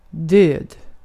Ääntäminen
IPA: /døːd/